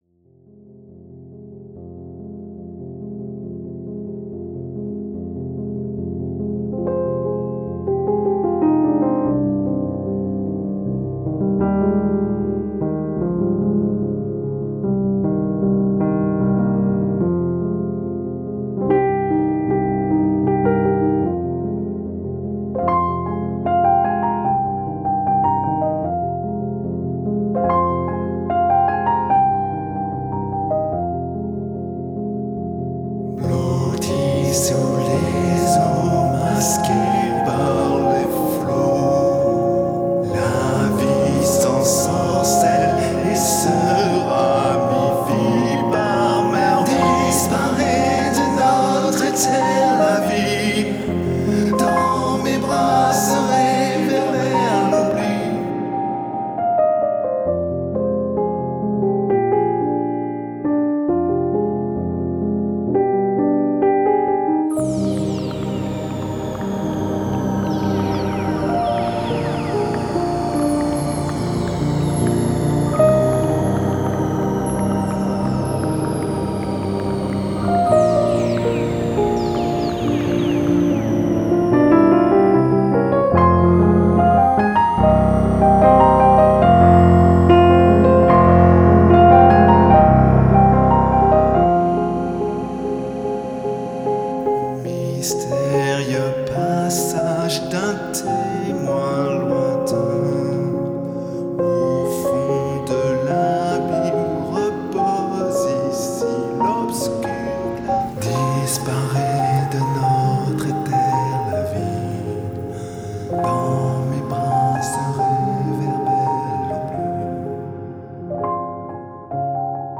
les pianos sont toujours très bien